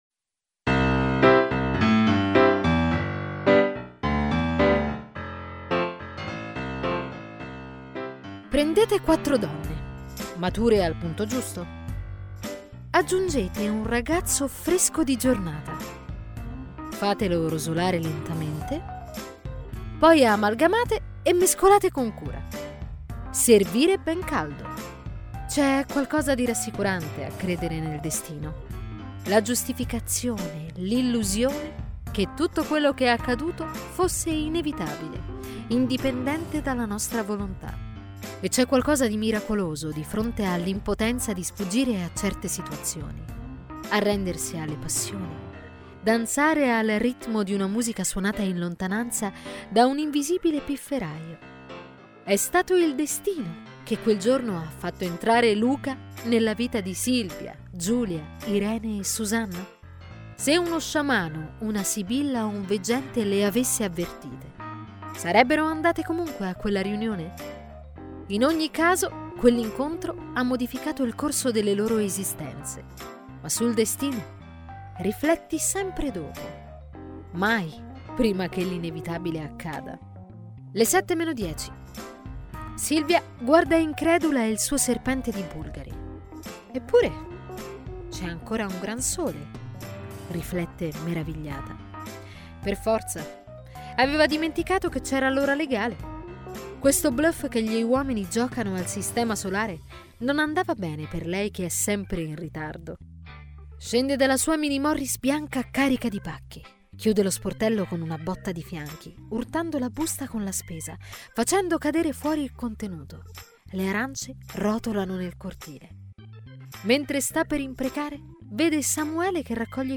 crediti musicali: Bensound